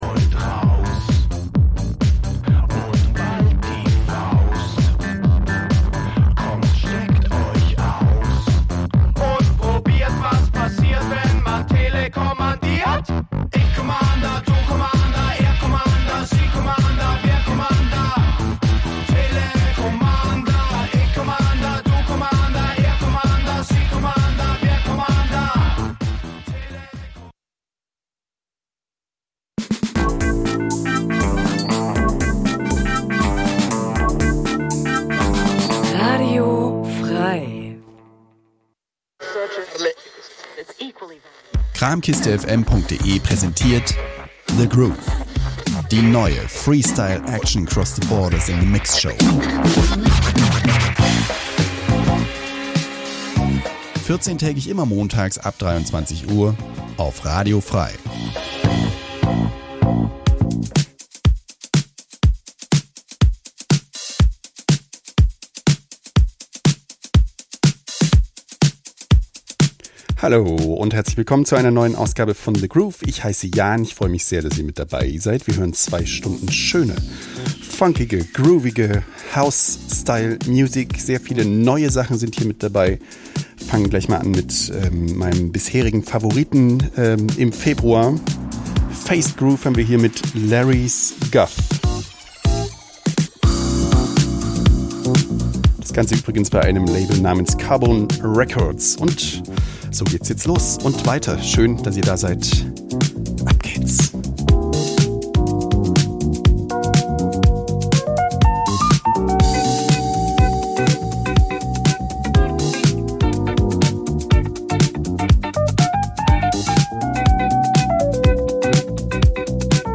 ... Experimental, Dub, Electronica, etc The Groove l�uft jeden zweiten Montag 23-01 Uhr und wird wechselnd am 1.
House, Drum�n�Bass, Breaks, Hip Hop, ...